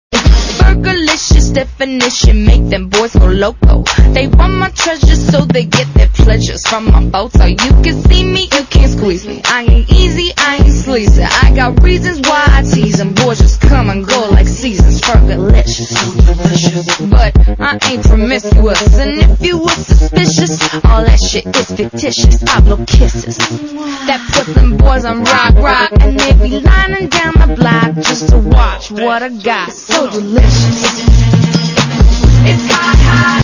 • Rap Ringtones